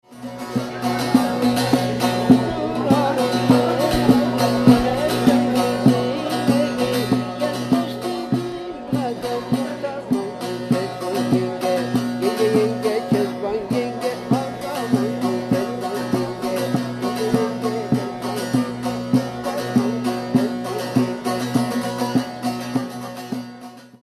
Mittagessen bei türkischer Musik bei Köprü
Die Klänge der Saz werden abgespielt, wenn im Firefox unter Extras - Einstellungen -Anwendungen für MP3 eine passende Anwendung eingestellt ist.
0141_mittagsmusik.mp3